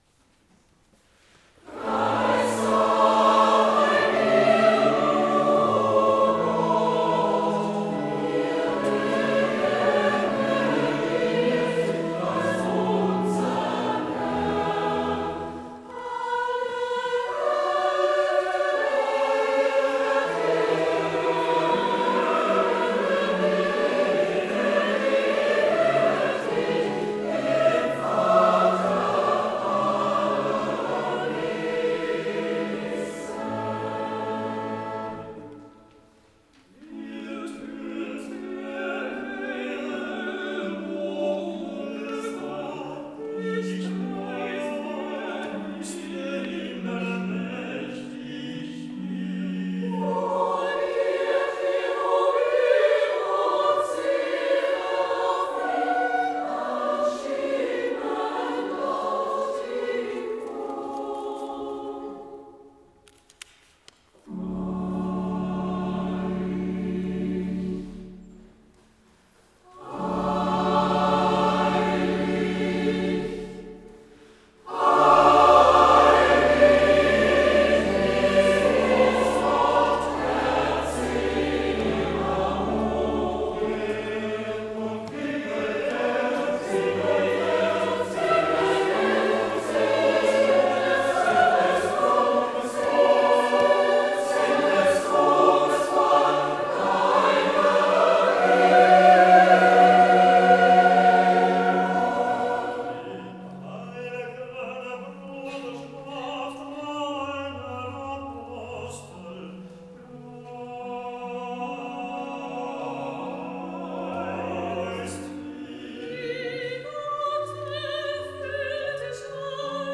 17.02.2019 (Christus-Kirche)
Perlen geistlicher Chormusik
für vierstimmigen Chor und Soli
Kammerorchester